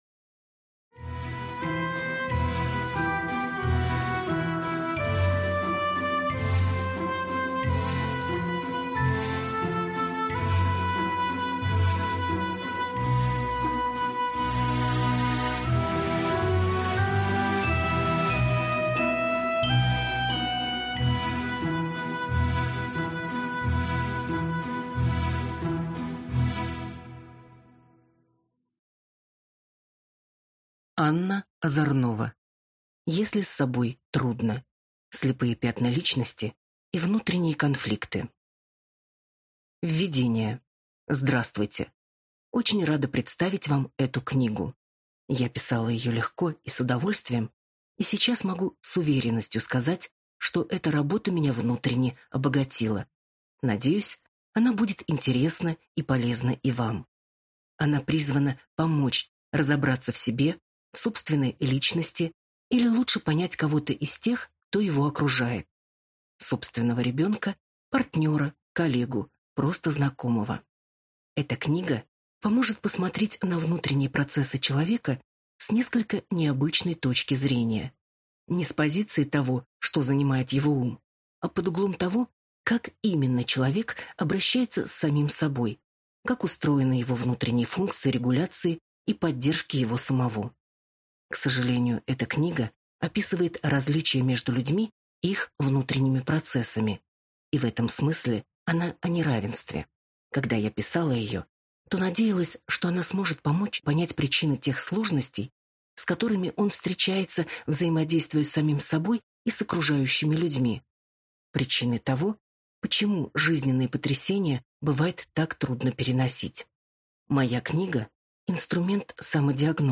Аудиокнига Если с собой трудно. «Слепые пятна» личности и внутренние конфликты | Библиотека аудиокниг